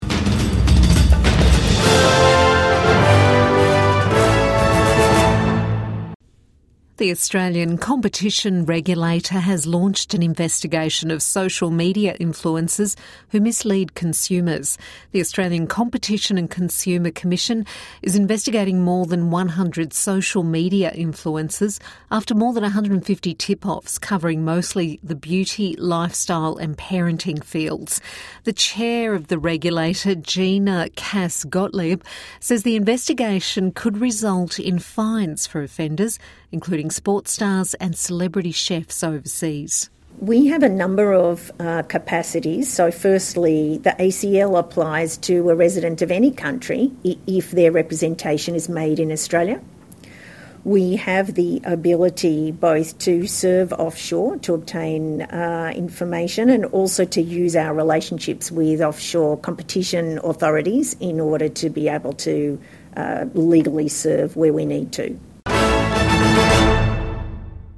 Social media influencers are being warned to be transparent when posting paid content because the ACCC is currently sweeping through hundreds of accounts. Hear from ACCC Chair Gina Cass-Gottlieb on why it's being done and the steps influencers can take to do the right thing